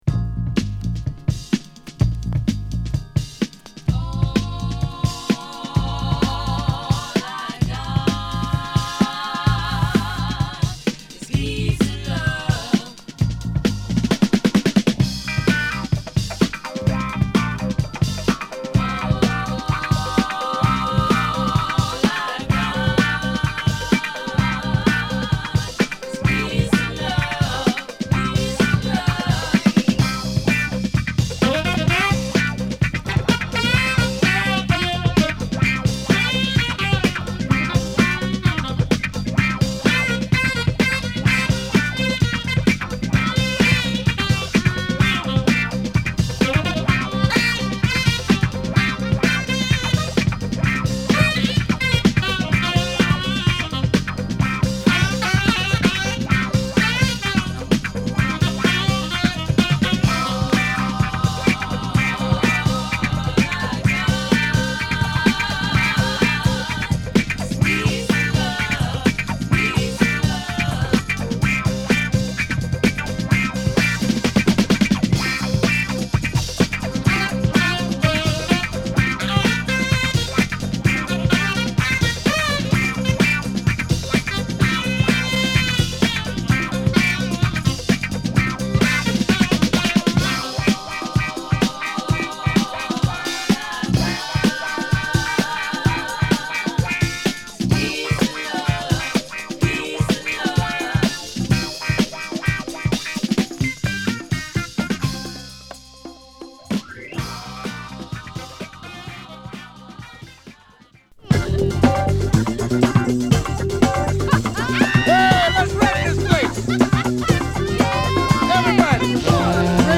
疾走感あるドラムに絡むギター、ファンキーなリズムの上で女性コーラスにサックスが絡むファンクダンサー！
＊擦れありますがPlay良好です。